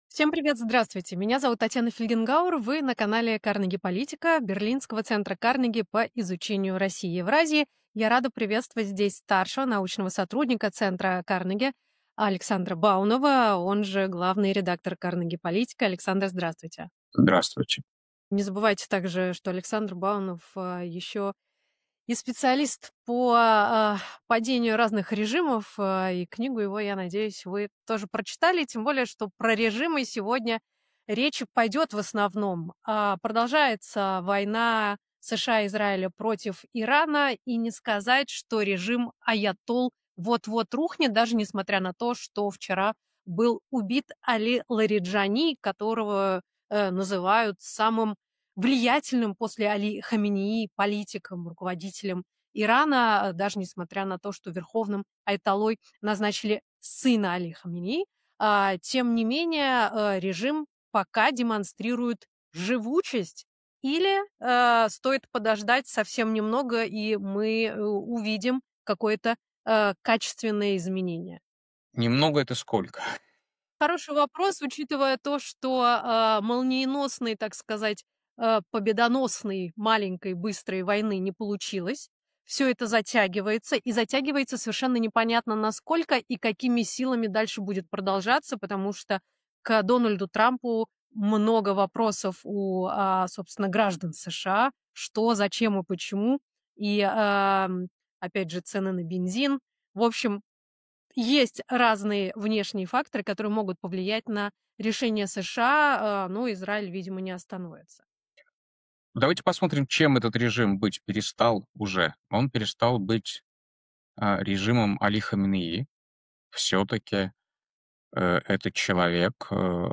Эфир ведёт Татьяна Фельгенгауэр